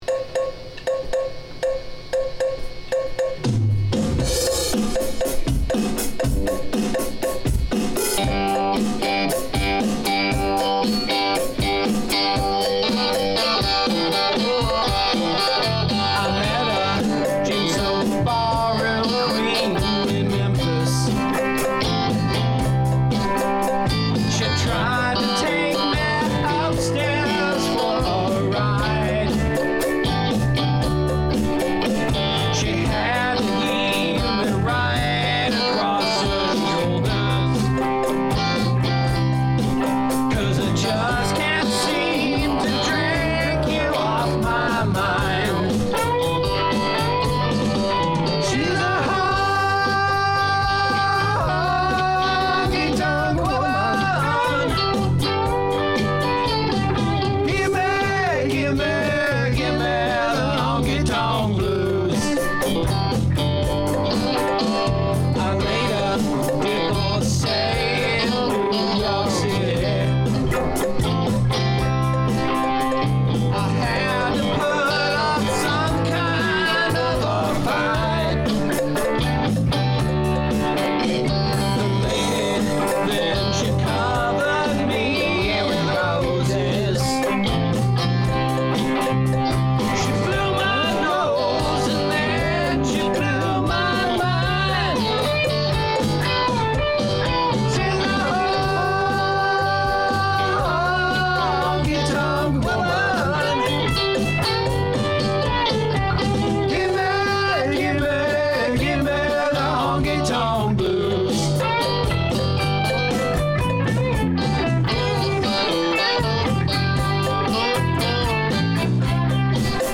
Room Mix.mp3